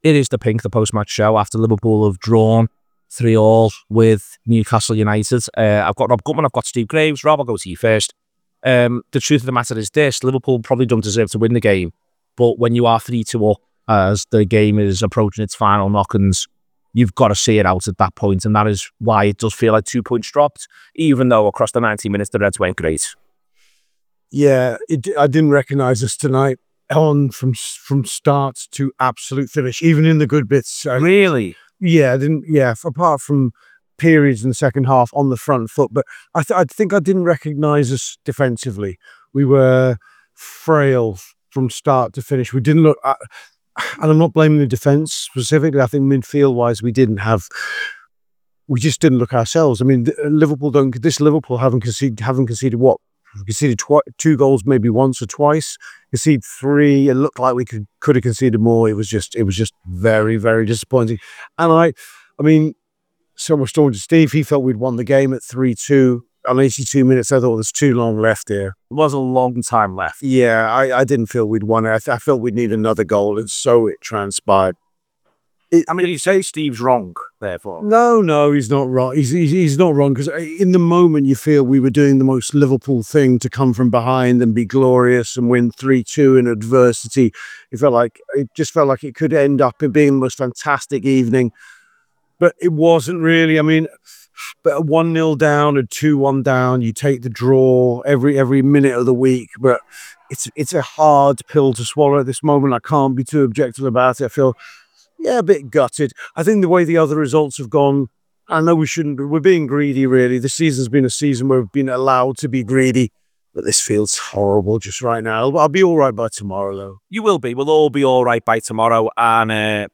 The Anfield Wrap’s post-match reaction podcast after Newcastle 3 Liverpool 3 at St James’ Park where the Reds, having came from behind to lead, conceded a late equaliser.